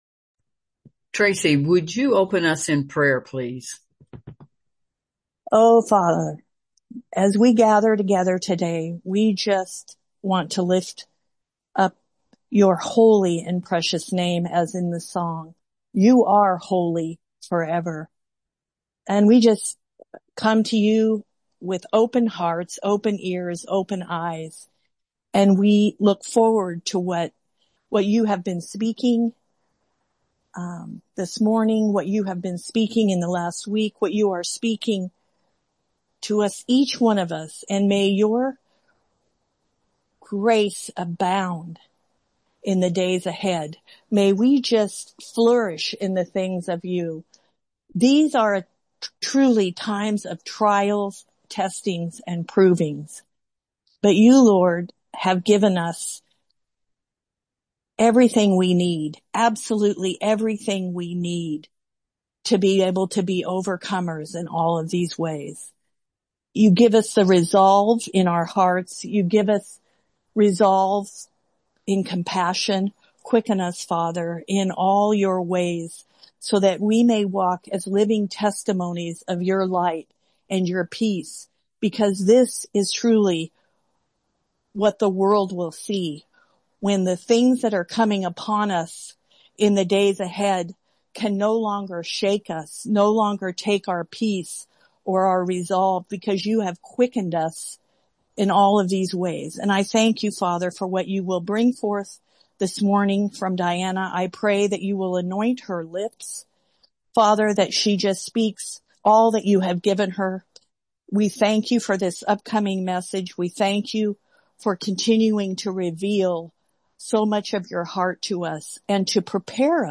The following is an excerpt from a fellowship meeting held, January 17, 2026, discussing how prophecy for our day is unfolding before our eyes.